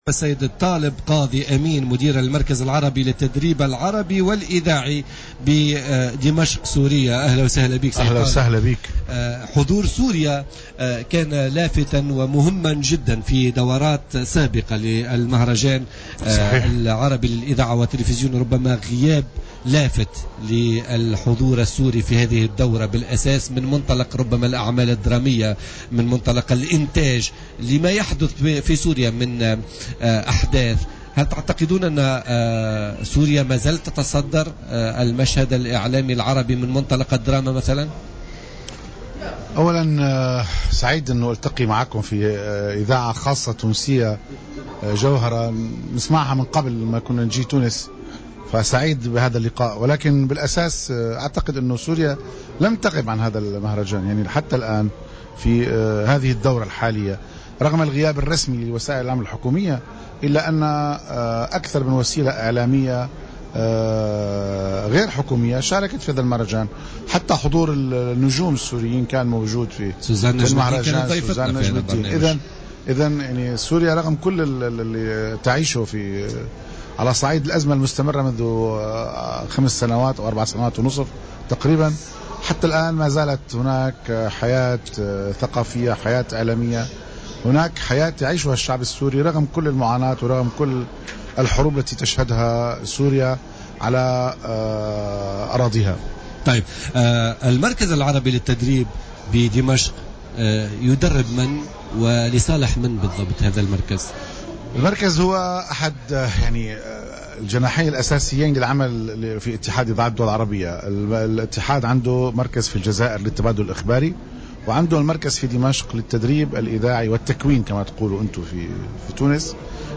ويواكب برنامج "بولتيكا" على "جوهرة أف أم" هذا الحدث مباشرة، حيث كان له اليوم الجمعة عدة مداخلات مع فاعلين في المجال الإذاعي والتلفزيوني الذي لاحظ بعضهم الغياب البارز لسوريا بعد أن كانت تستأثر بنصيب الأسد من حيث أعمالها الدرامية أساسا فيما تحاول مصر حاليا استرجاع مركزها في هذا المجال الذي احتكرته سوريا في السنوات السابقة وقبل اندلاع الحرب في سوريا.